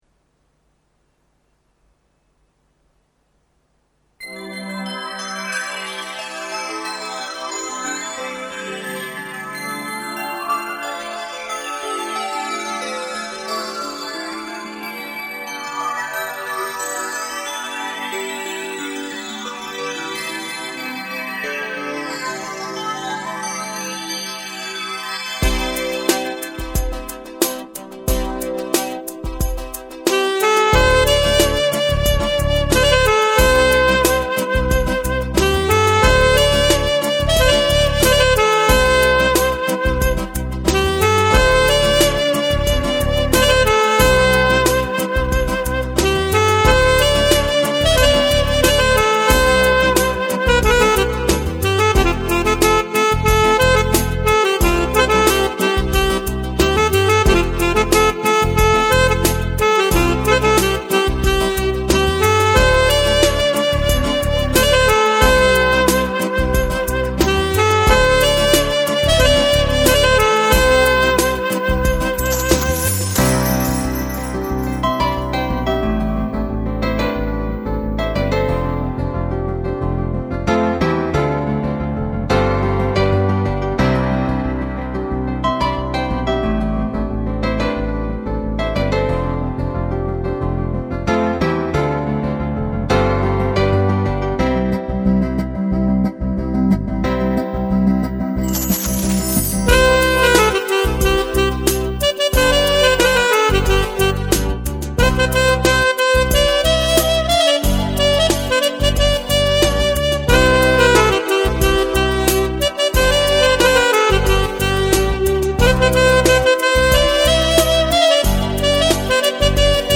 Instrumental Songs